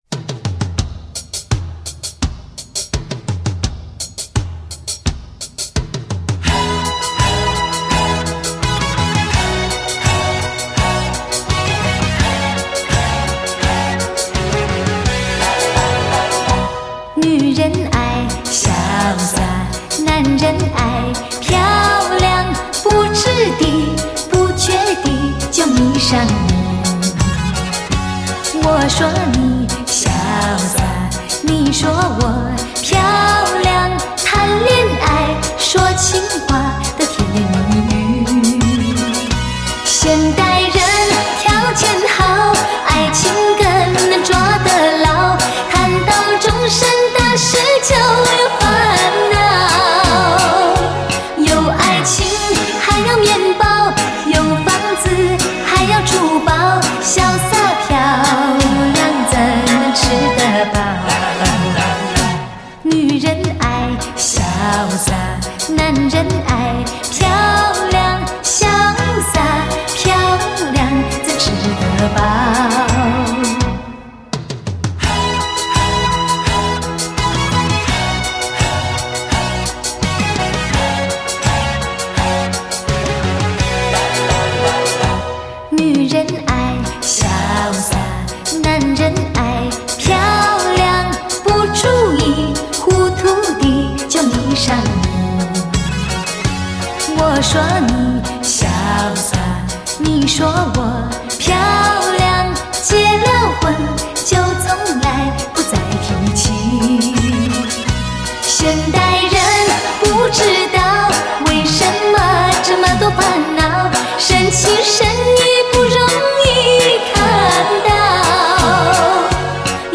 洒脱不羁又不乏绵绵情意的乐曲，容颜终会老去，深情深意却不容易看到。